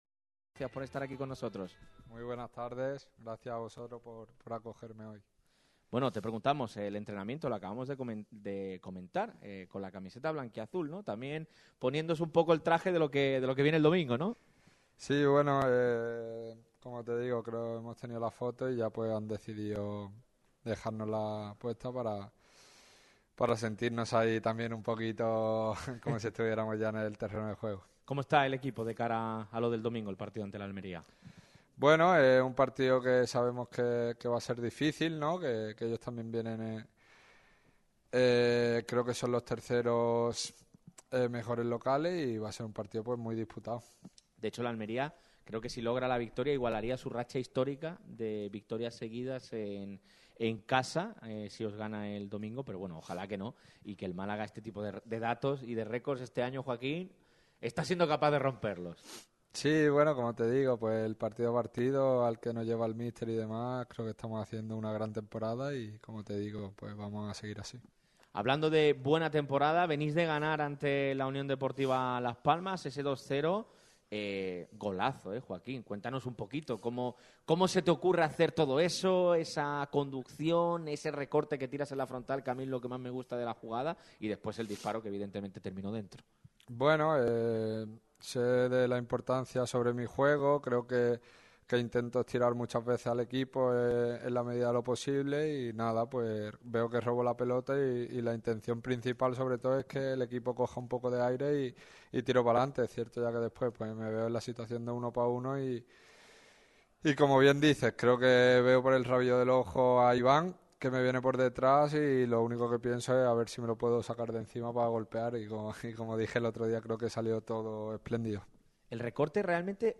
Entrevistón hoy en Radio MARCA Málaga. Joaquín Muñoz se ha pasado por el micrófono rojo de la radio del deporte en un momento crucial del curso.